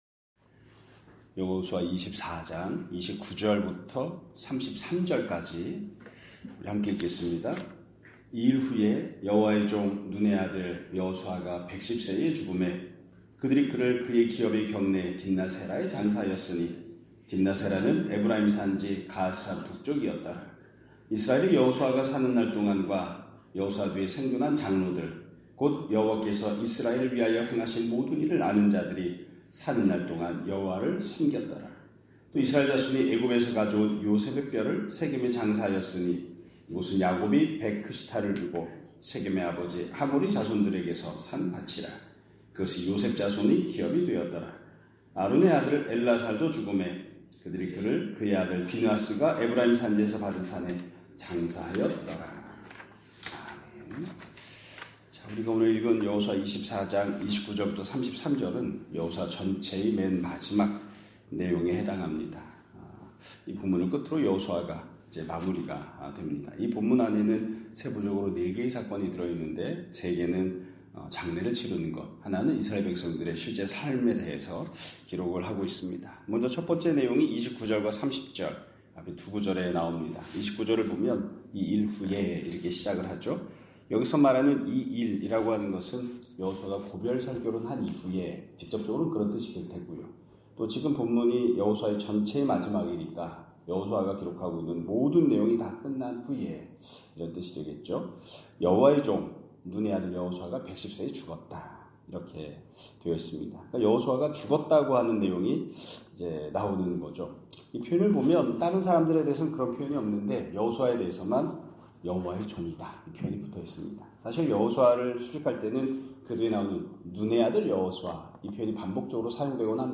2025년 3월 6일(목 요일) <아침예배> 설교입니다.